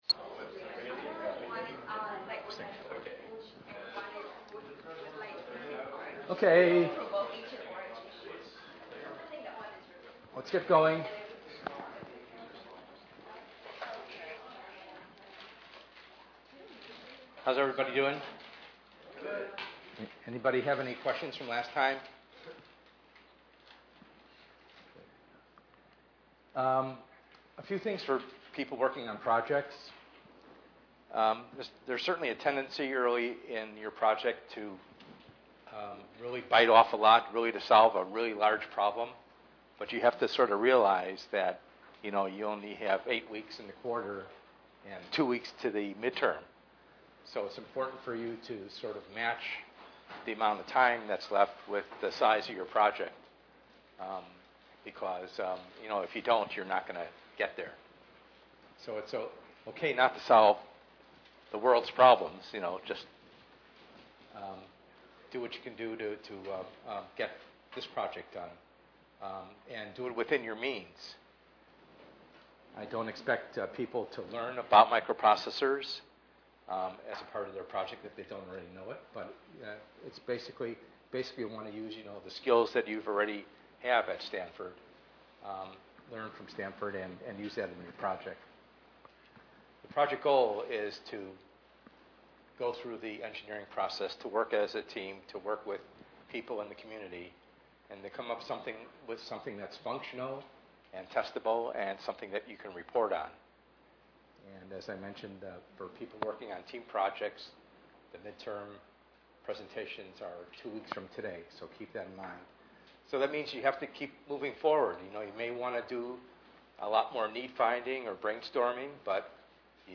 ENGR110/210: Perspectives in Assistive Technology - Lecture 04b